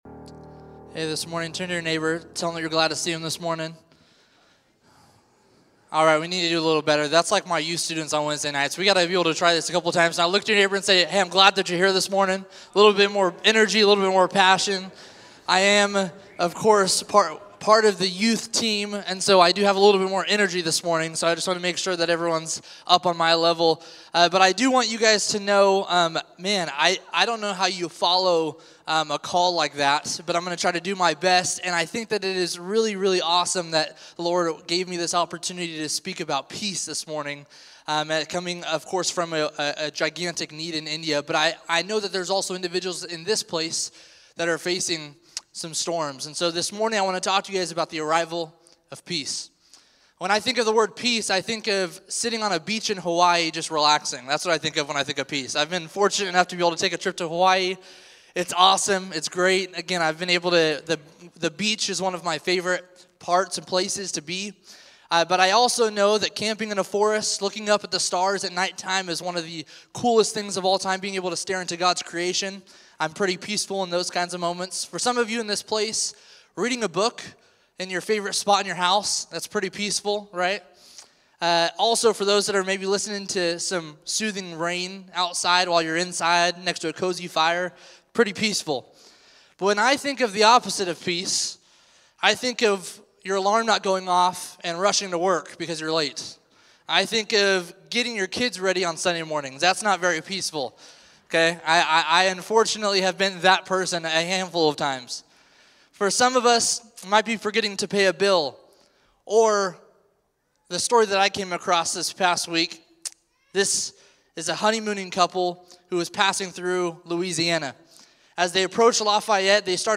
Sermons | Parkway Christian Center